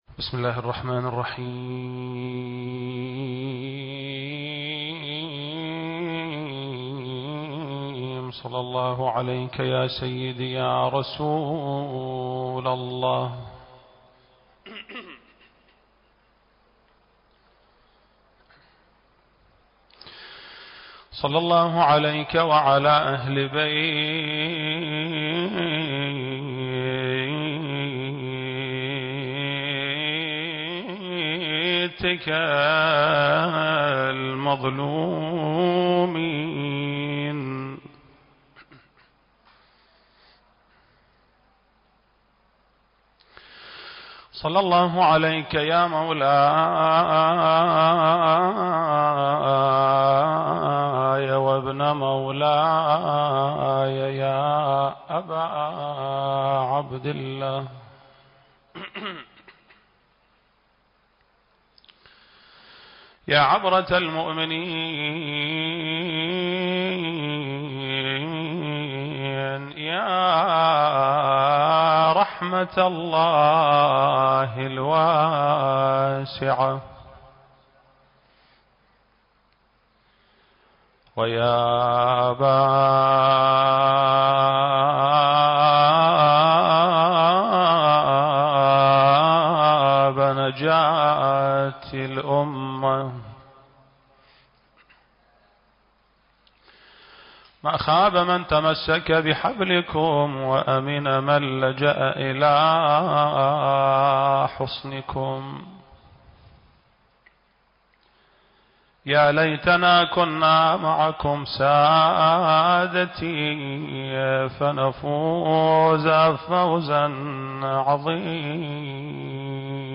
المكان: مسجد آل محمد (صلّى الله عليه وآله وسلم) - البصرة التاريخ: 1442 للهجرة